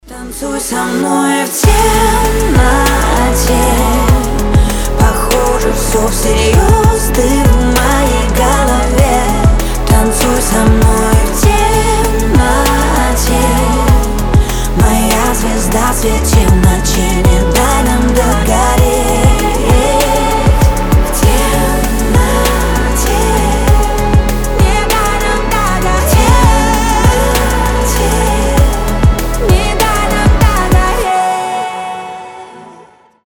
• Качество: 320, Stereo
красивые
медленные
красивый женский голос